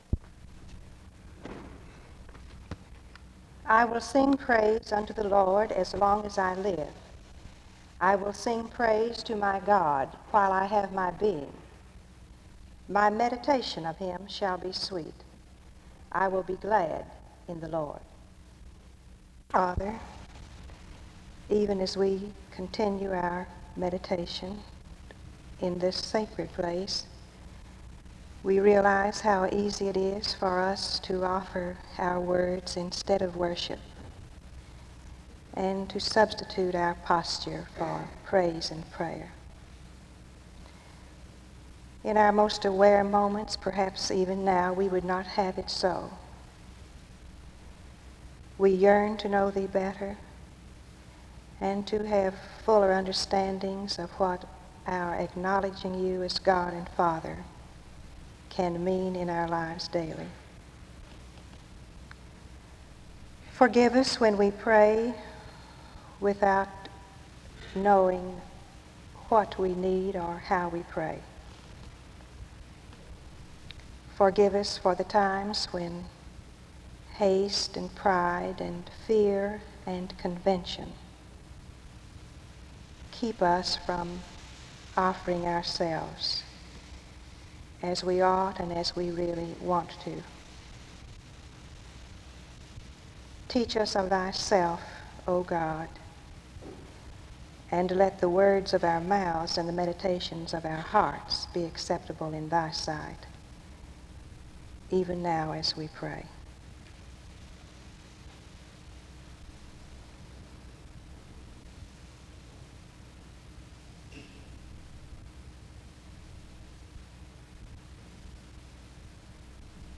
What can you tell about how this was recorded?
The service concluded with a song and prayer (9:51-end).